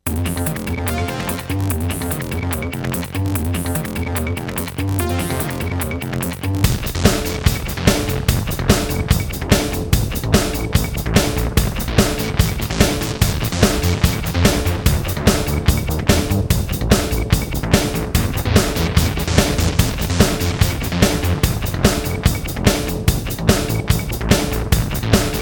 Featured in Electro RIngtones